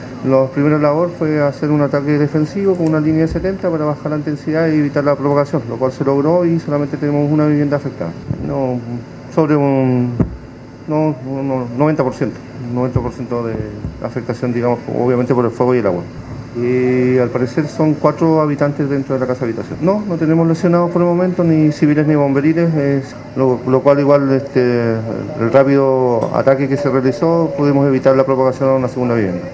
El jefe bomberil indicó que rápidamente llegaron al lugar las unidades para un combate a las llamas, que amenazaban con expandirse hacia casas colindantes, dejando alrededor de cuatro personas afectadas por la pérdida de todos sus enseres.